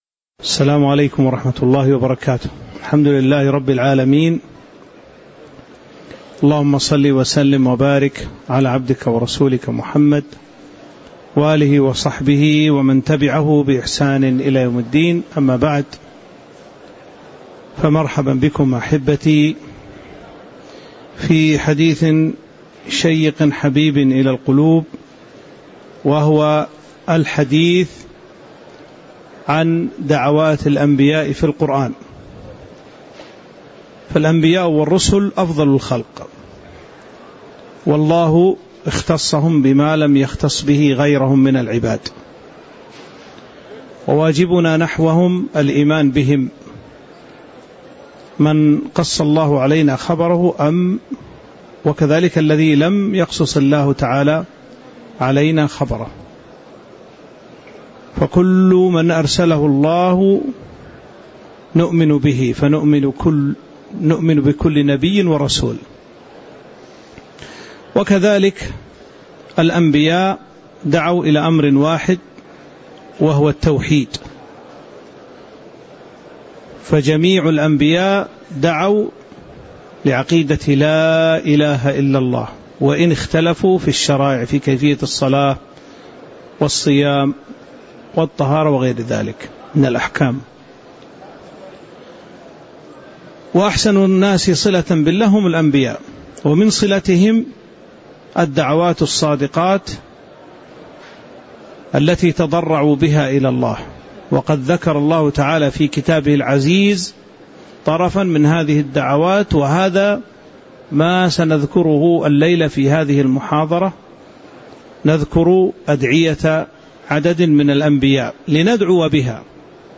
تاريخ النشر ١٩ ذو الحجة ١٤٤٦ هـ المكان: المسجد النبوي الشيخ